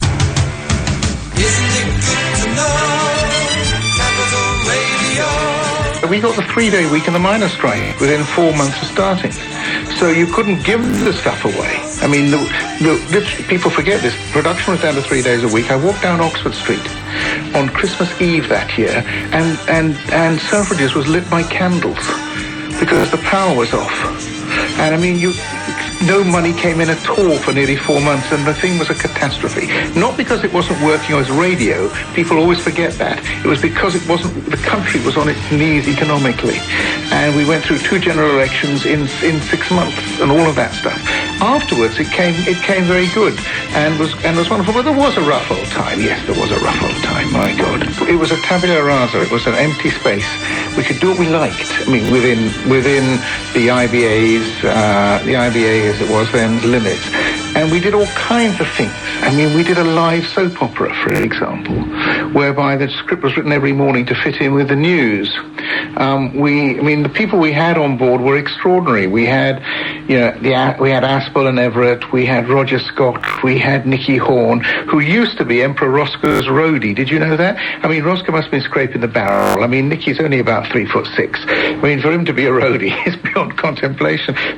In this audio, hear Michael tell in his engaging, fruity, no-nonsense style of those tough early Capital days.